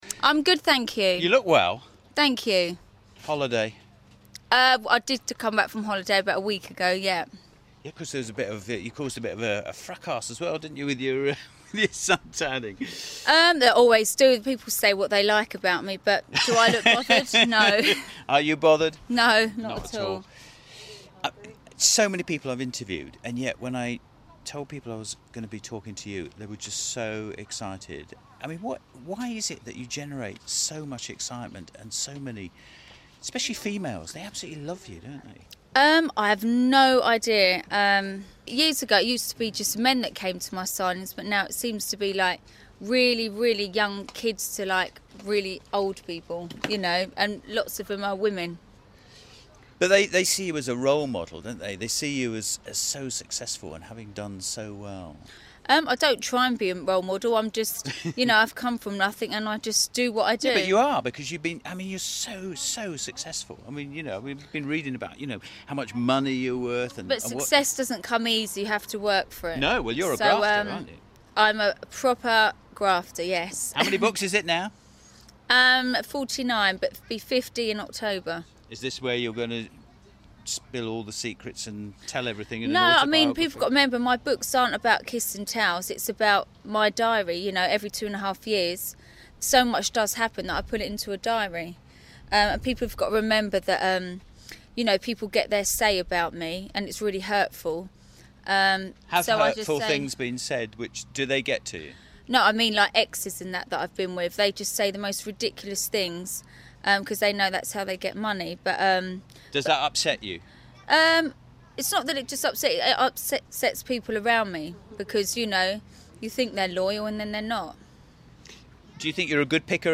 Katie came to Grimsby to sign copies of her new book. Peter had a chat with her on the steps of her giant pink horsebox.